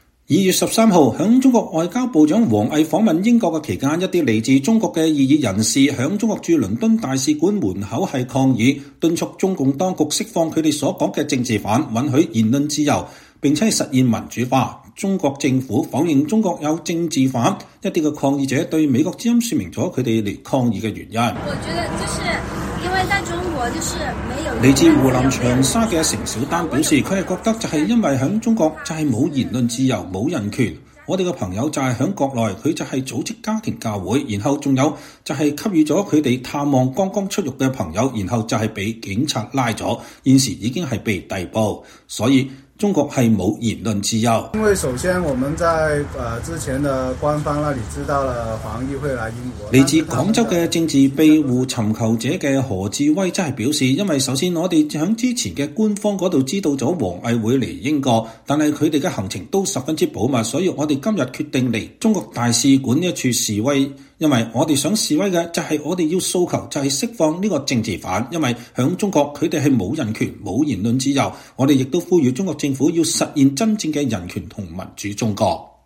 一些來自中國的異議人士在中國駐倫敦大使館門口抗議，
一些抗議者對美國之音說明了他們來抗議的原因。